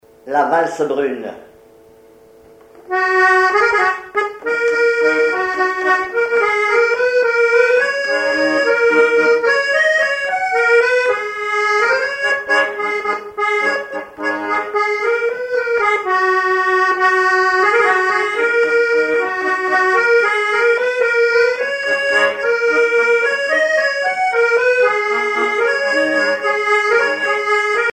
accordéon(s), accordéoniste
valse musette
Répertoire à l'accordéon chromatique
Pièce musicale inédite